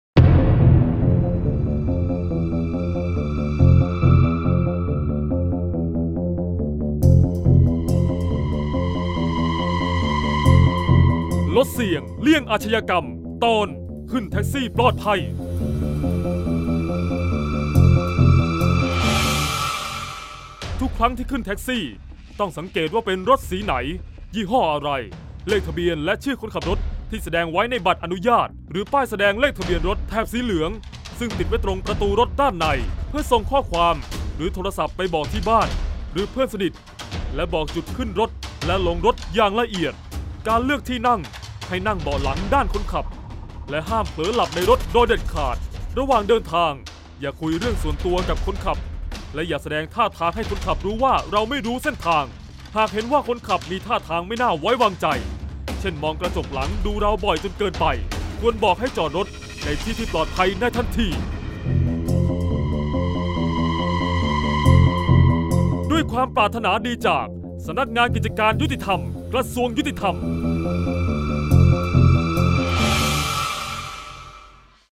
เสียงบรรยาย ลดเสี่ยงเลี่ยงอาชญากรรม 21-ขึ้นแท็กซี่ปลอดภัย